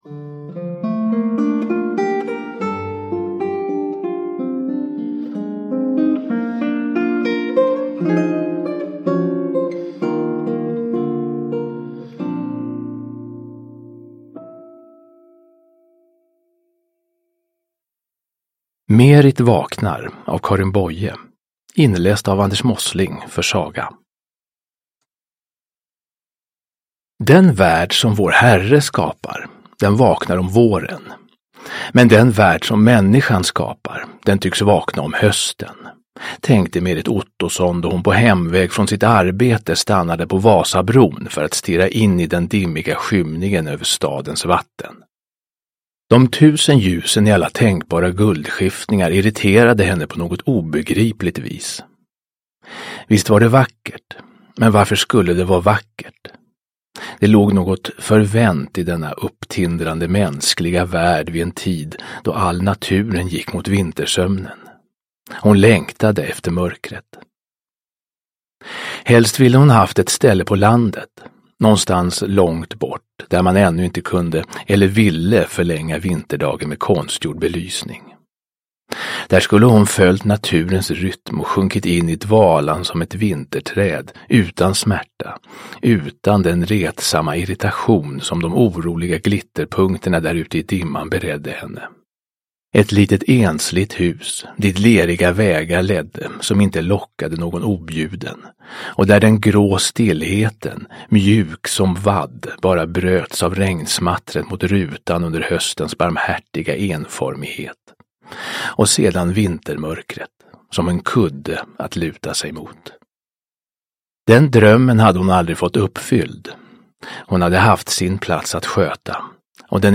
Merit vaknar – Ljudbok – Laddas ner